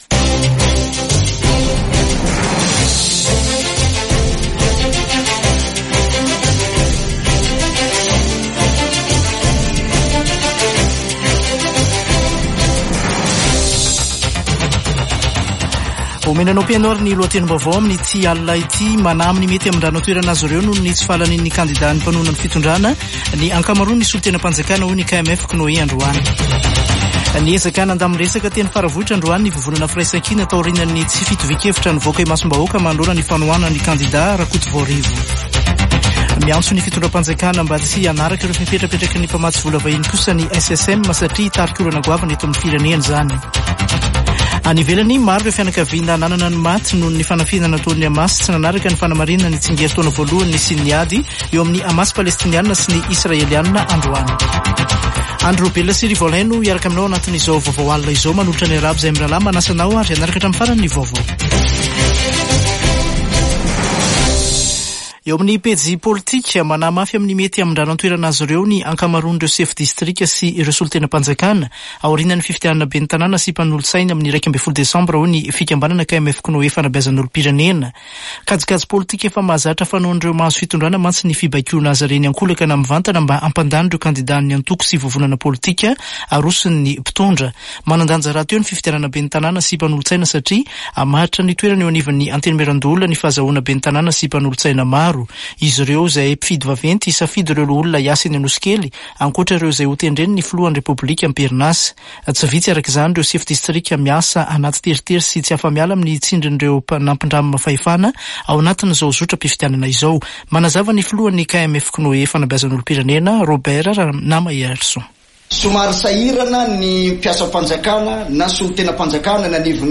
[Vaovao hariva] Alatsinainy 7 ôktôbra 2024